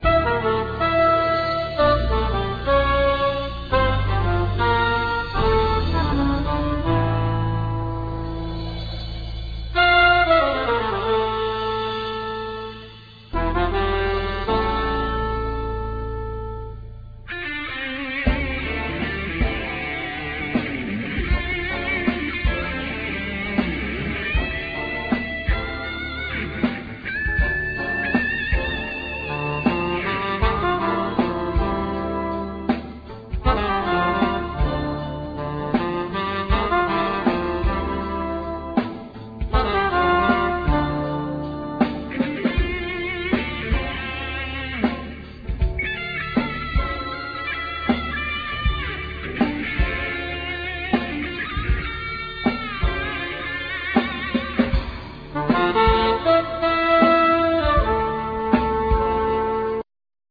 Trumpet,Flugelhorn
Soprano+Tenor Saxophone
Keyboards,Piano,Percussions
Bass
Drums,Percussions
Guitar